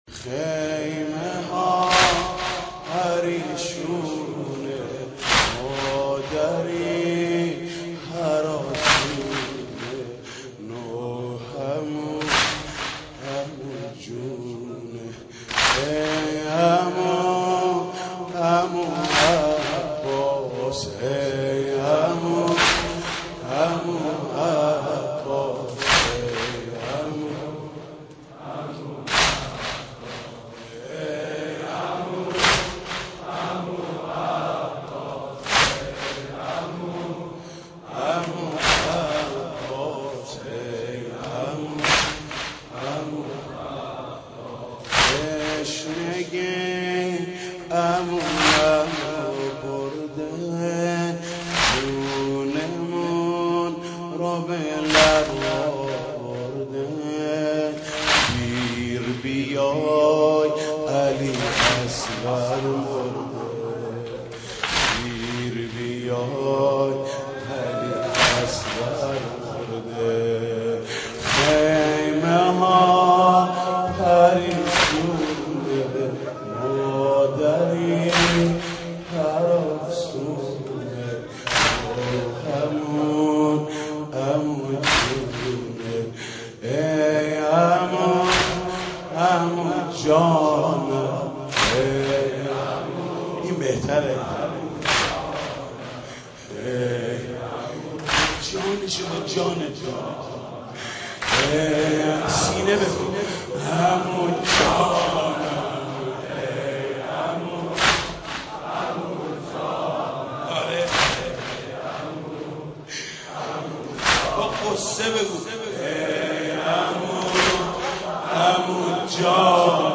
عنوان : نوحه واحد شب نهم محرم